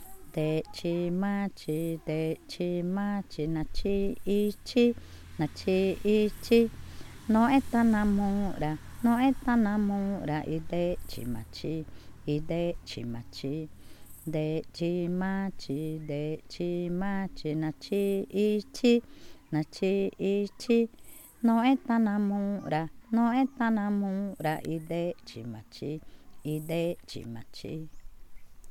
Cushillococha
Canción infantil
en Cushillococha (2023)